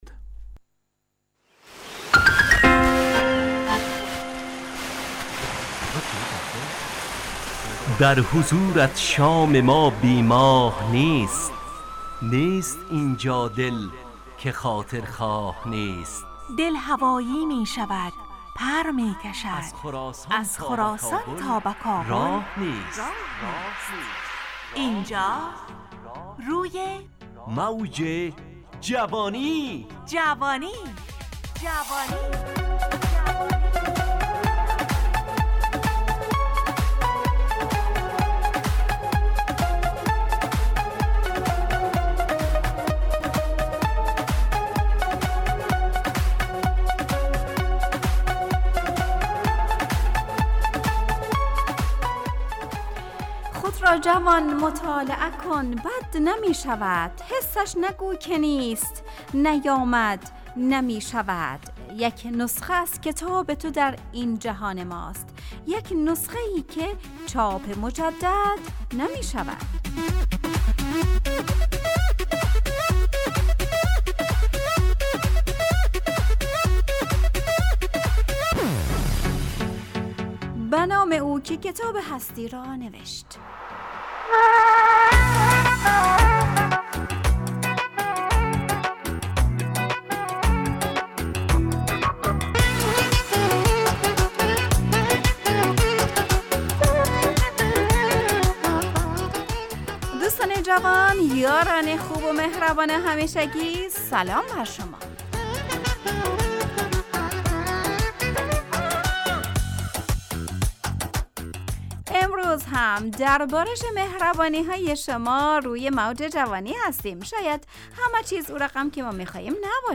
همراه با ترانه و موسیقی مدت برنامه 55 دقیقه . بحث محوری این هفته (اگر کتاب نخوانیم ... ) تهیه کننده